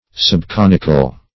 Subconical \Sub*con"ic*al\, a. Slightly conical.